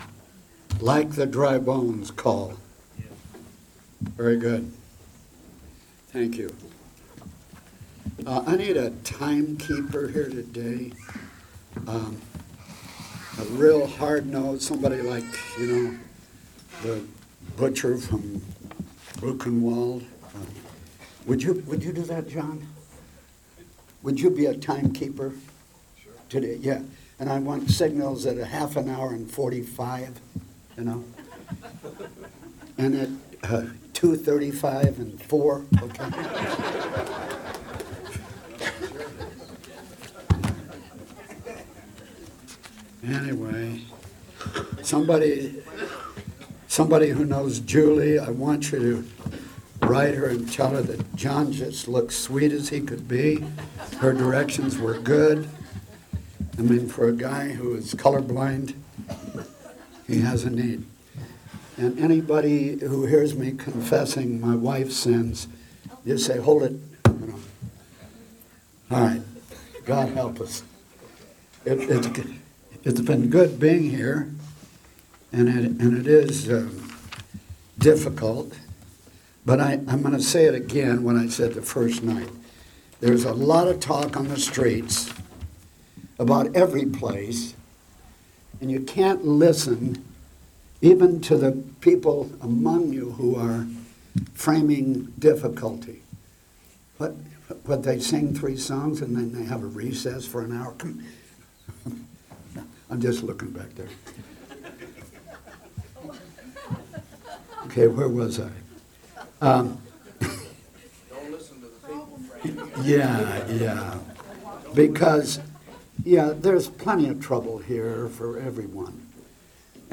Shepherds Christian Centre Convention